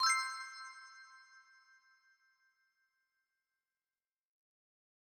week7-brrring.mp3